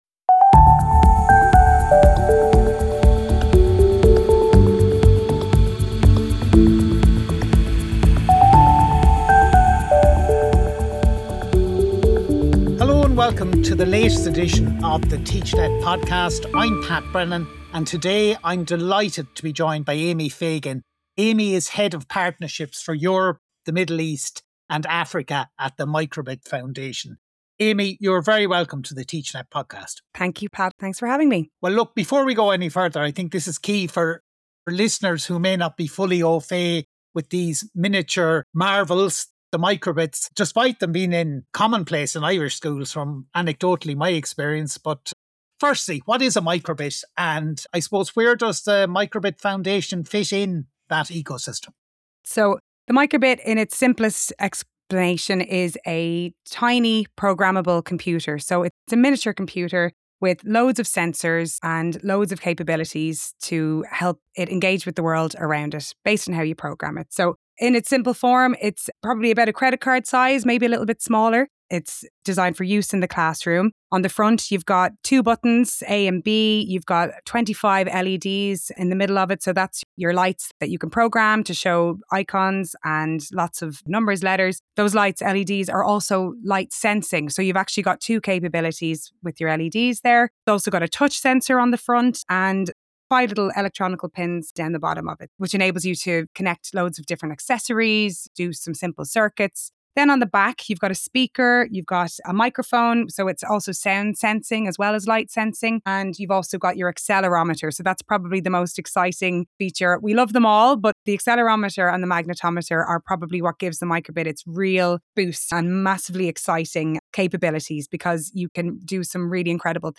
This conversation focuses on equity, access, and practical pathways for teachers, regardless of prior technical experience.